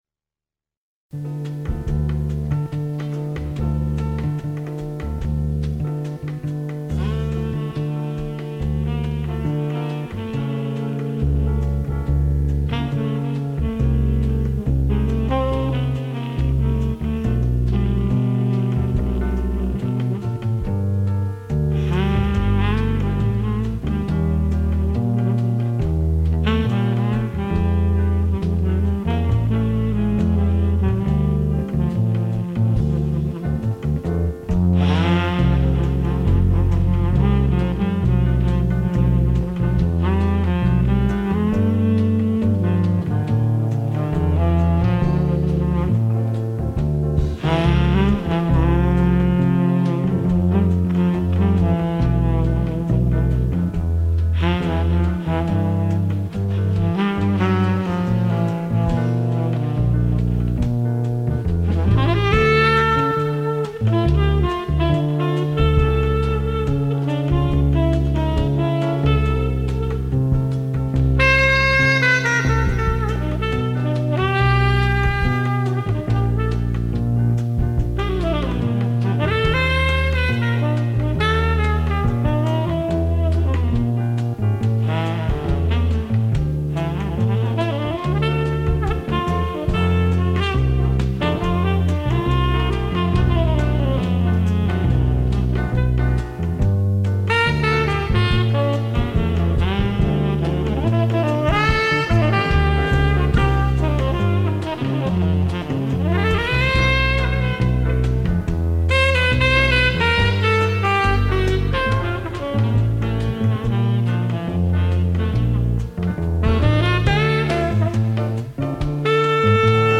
джазовых композиций